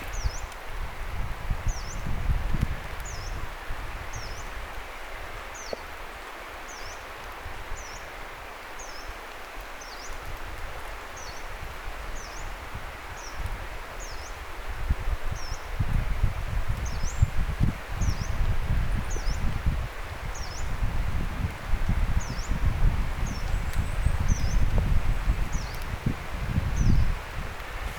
tässäkin vähän pidemmästi
taigauunilintu_saaressa_aika_lahella.mp3